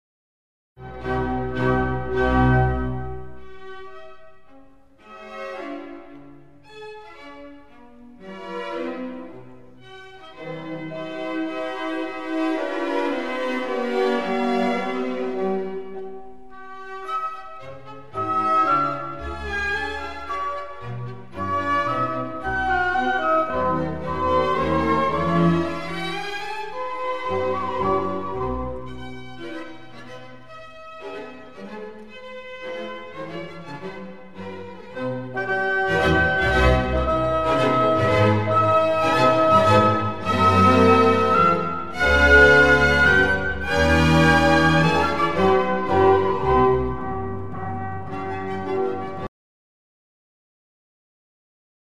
2. Satz - Sonatenhauptsatzform, F-Dur (4. Stufe von C-Dur)